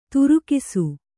♪ turukisu